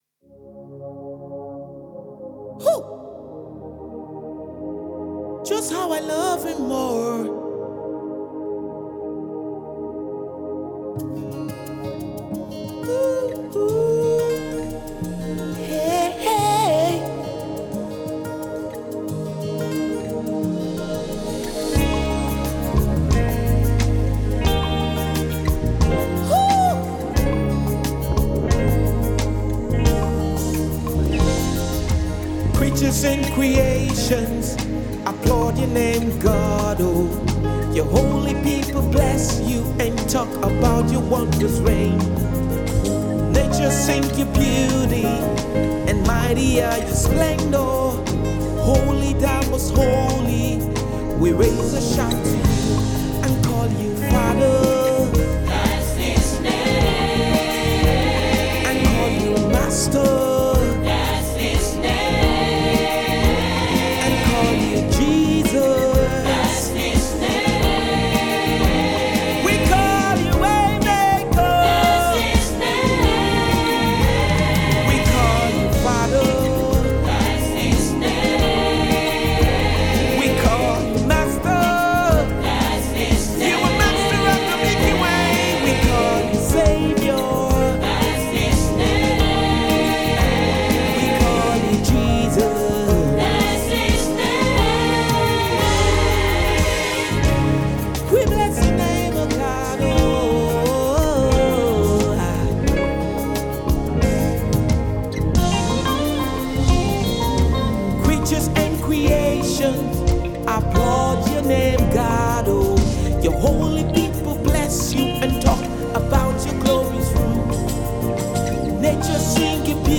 Nigerian Manchester-based gospel music minister